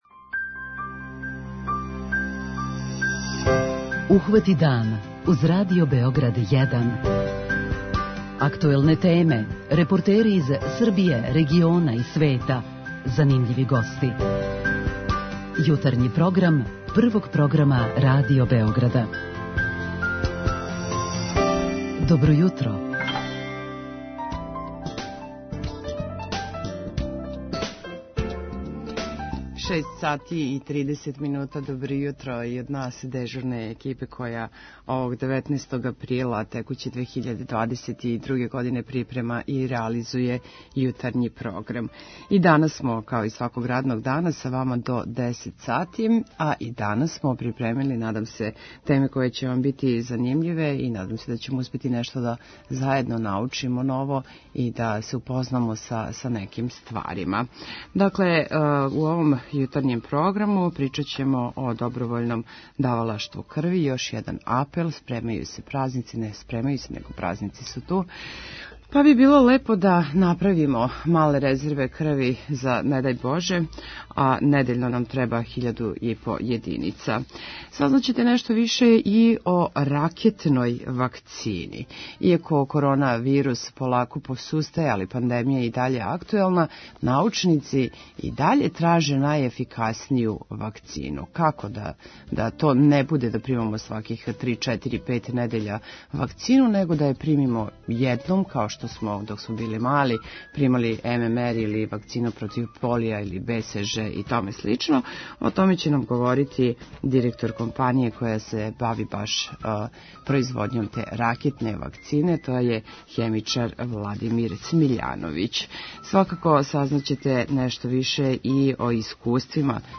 преузми : 37.78 MB Ухвати дан Autor: Група аутора Јутарњи програм Радио Београда 1!